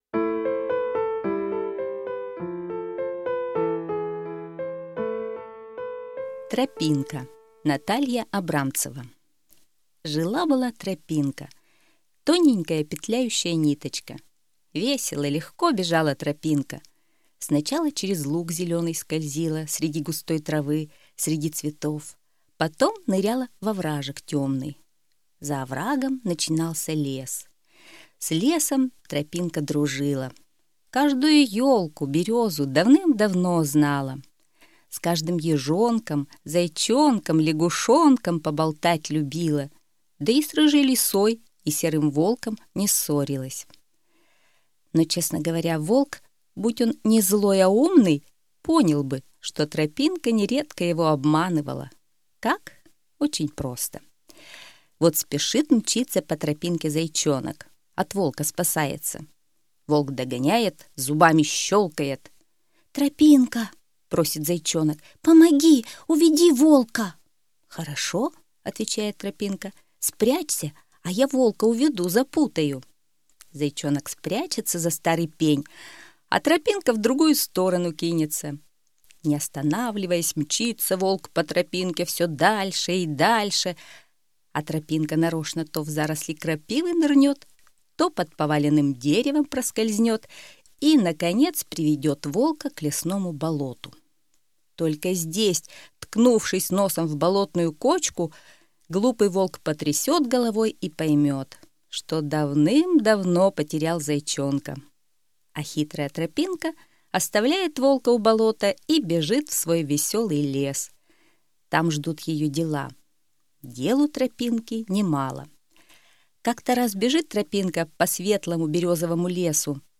Аудиосказка «Тропинка»